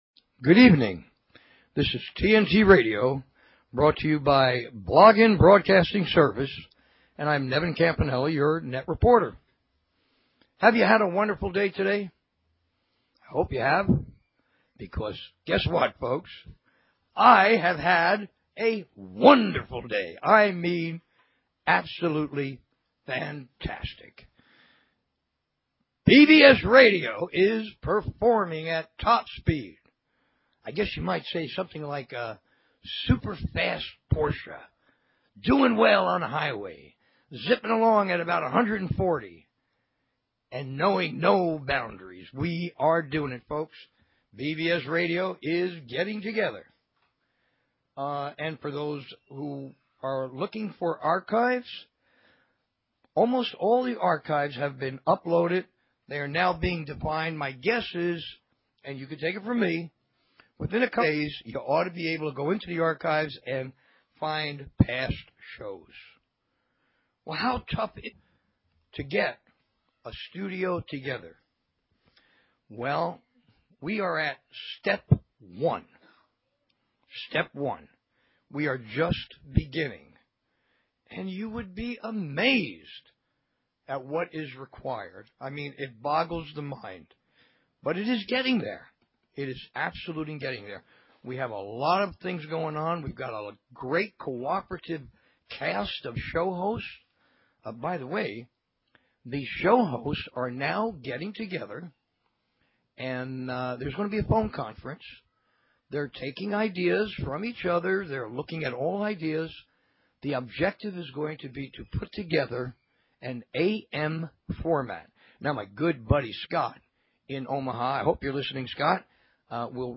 Talk Show Episode, Audio Podcast, TNT_Radio and Courtesy of BBS Radio on , show guests , about , categorized as